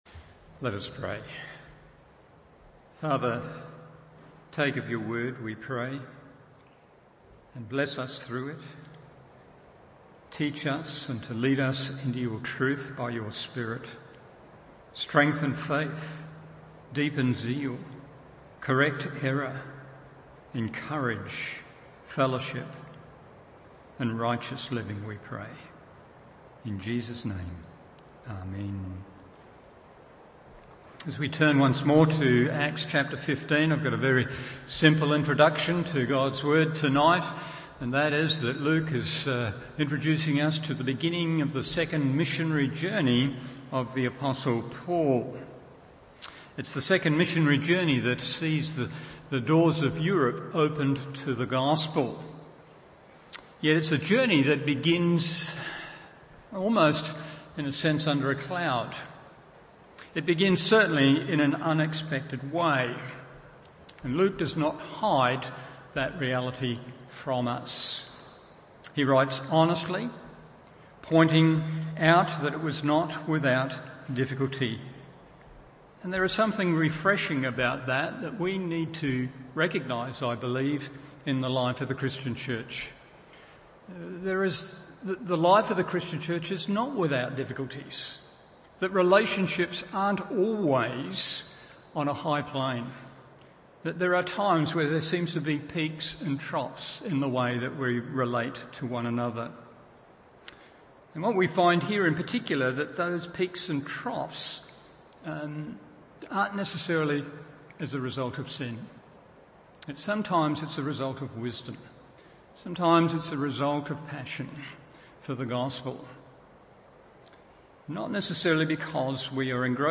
Evening Service Acts 15:36-16:5 1. The Work was not Finished 2.